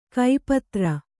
♪ kai patra